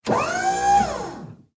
iron-man-sound_24893.mp3